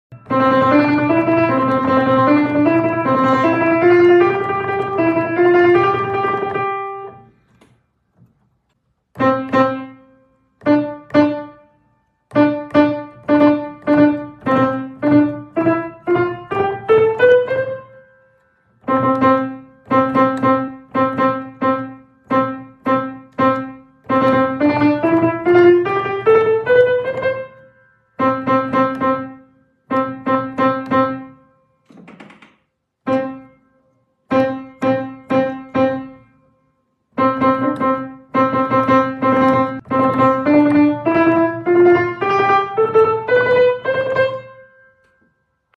Using forearm rotation inwards to play the thumb will speed things up.(More about rotation in future videos!)Who can hear the melody in the beginning?